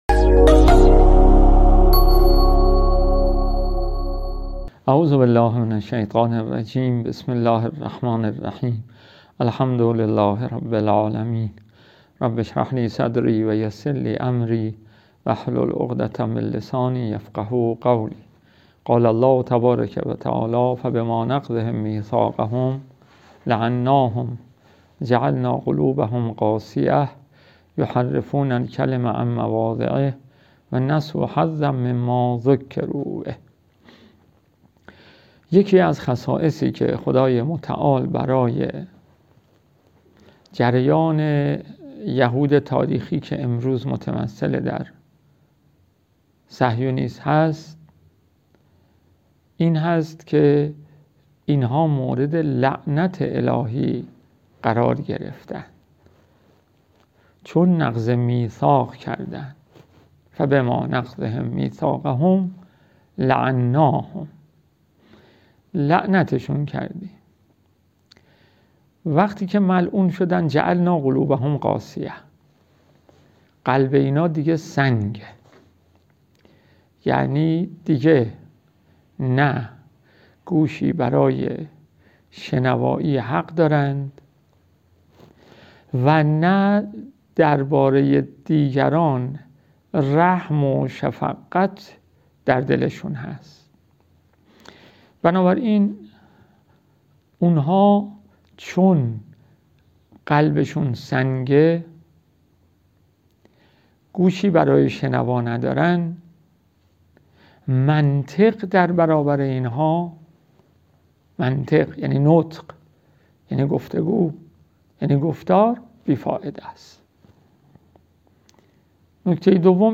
صوت سخنرانی‌ها